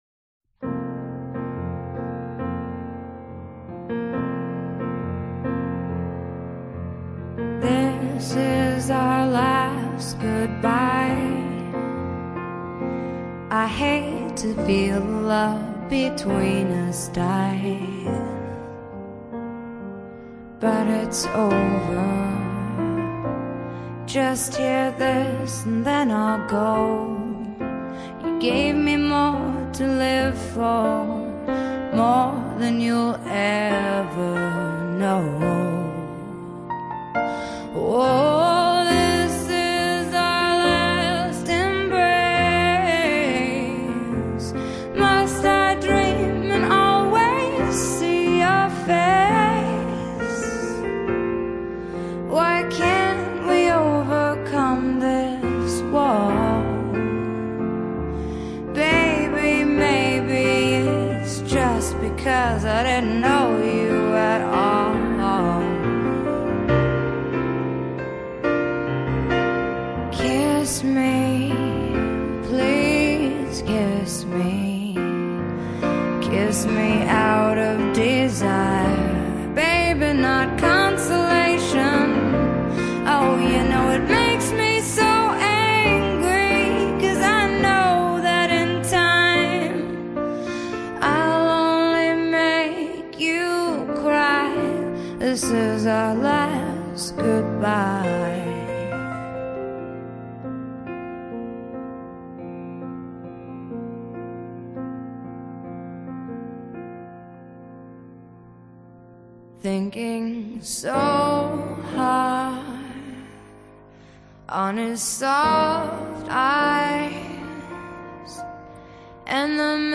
Là c’est dépouillé, c’est mieux.